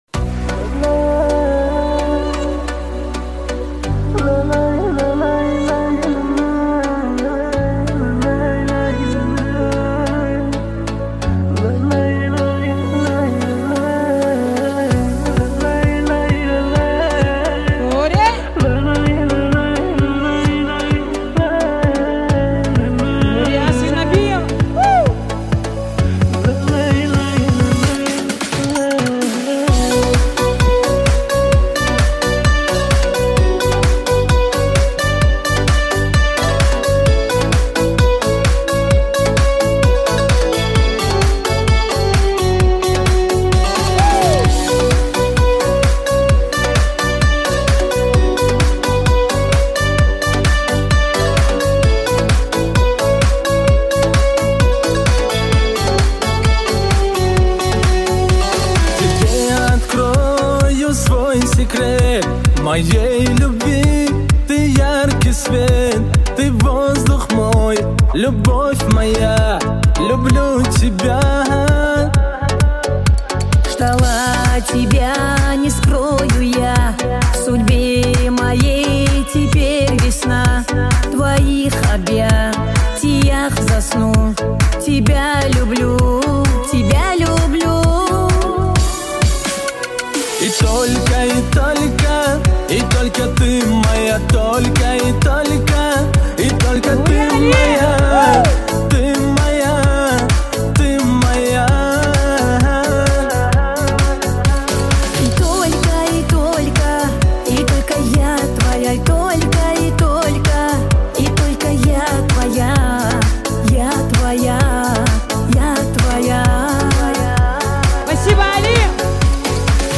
Concert version